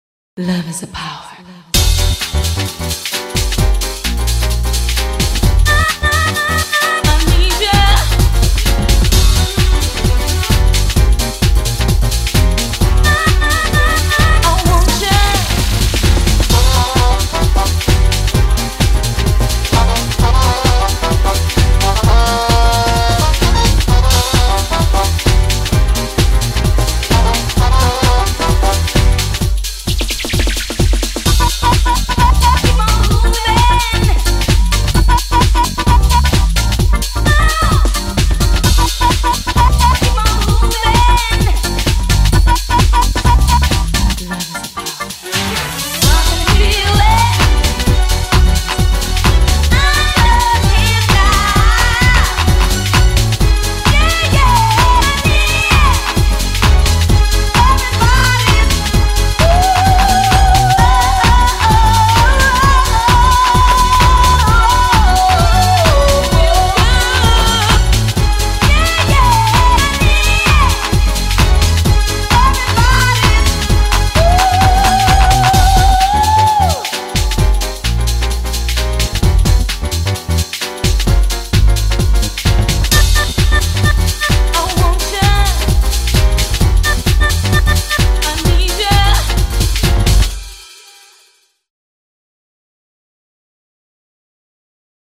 BPM130
Audio QualityPerfect (High Quality)
Comments* It's real BPM is 130.1